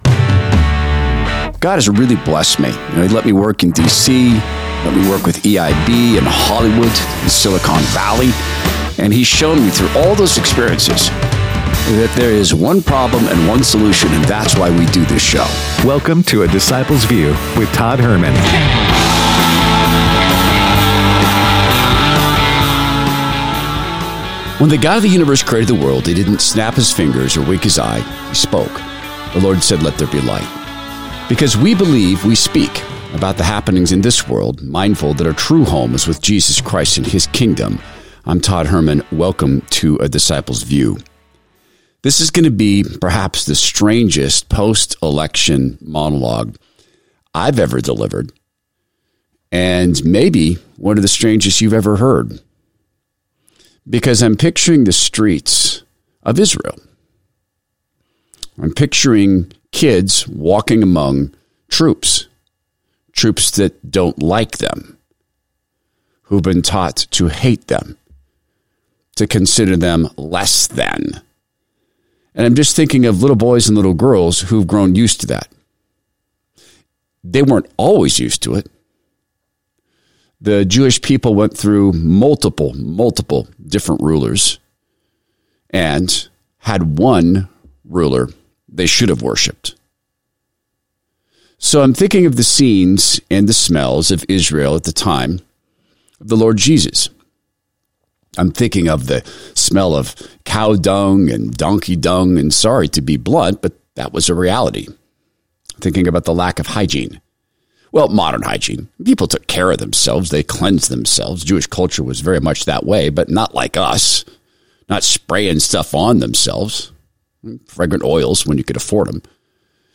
A Post Election Monologue